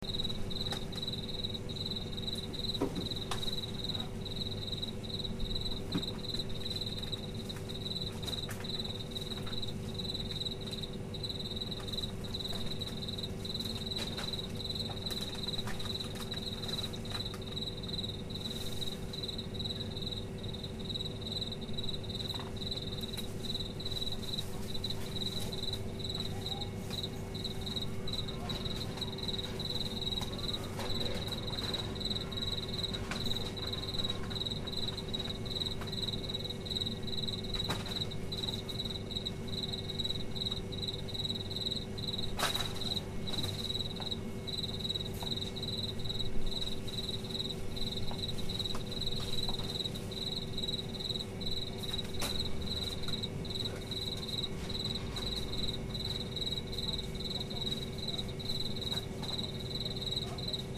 kuehltheke.mp3